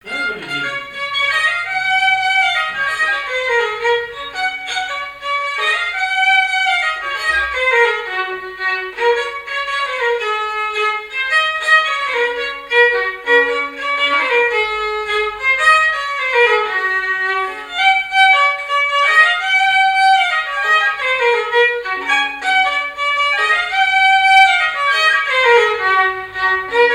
Saint-Marsault
Couplets à danser
branle : avant-deux
répertoire musical au violon